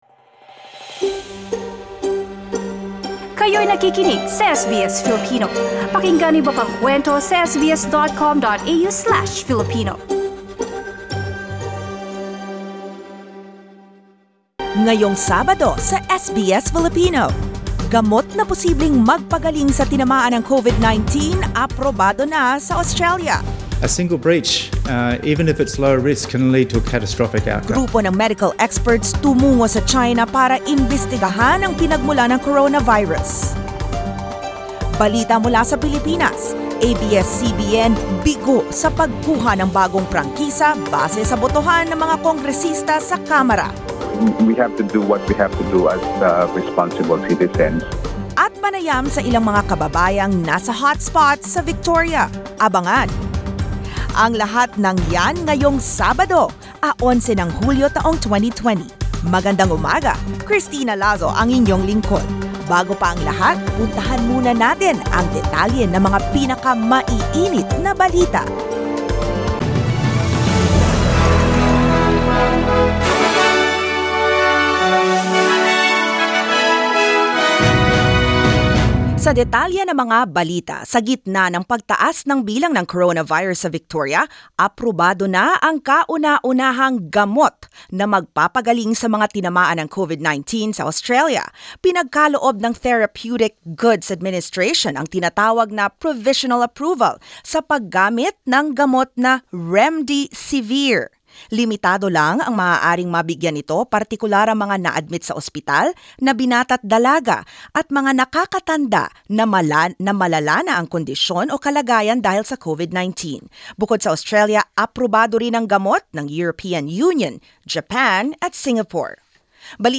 SBS News in Filipino, Saturday 11 July